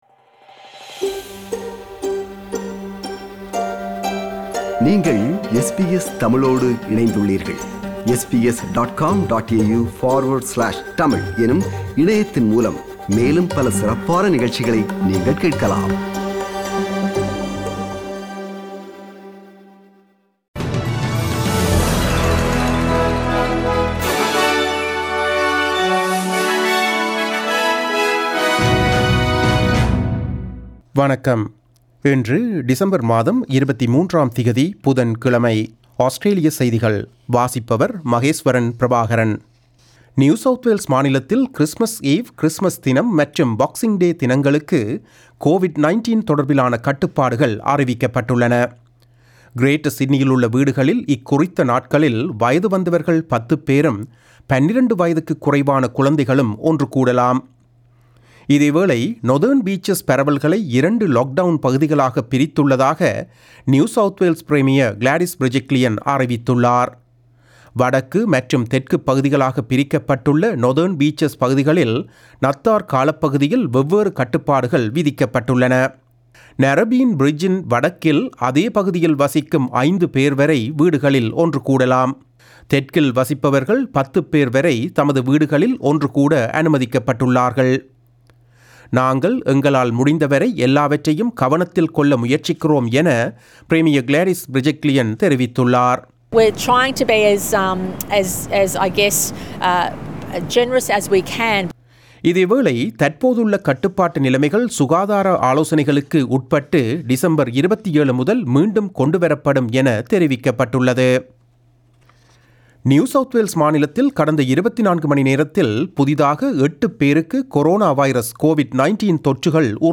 Australian news bulletin for Wednesday 23 December 2020.